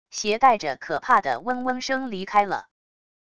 携带着可怕的嗡嗡声离开了wav音频